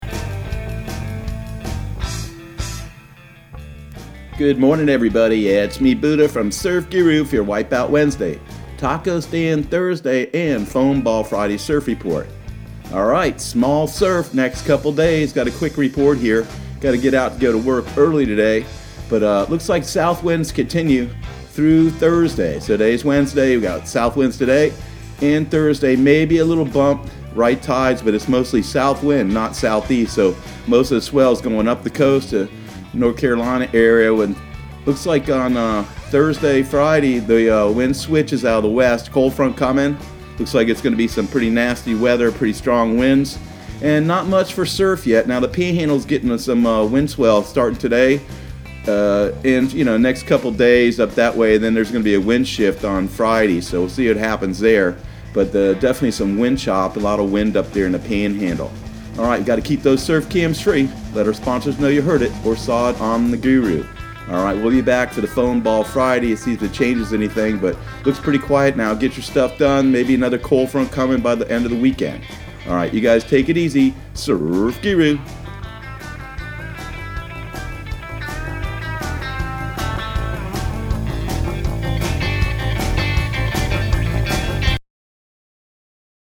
Surf Guru Surf Report and Forecast 02/05/2020 Audio surf report and surf forecast on February 05 for Central Florida and the Southeast.